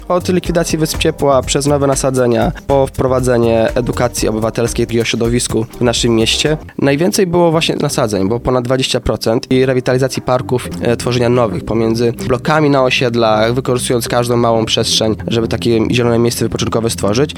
Jakie propozycje najczęściej były wpisywane mówi reprezentant Stowarzyszenia „Łączy nas Radom”: